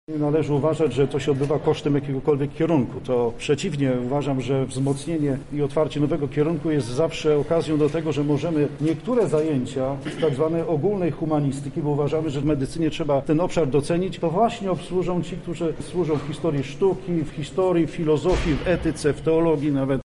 • mówi ks. prof. dr hab. Mirosław Kalinowski, rektor KUL.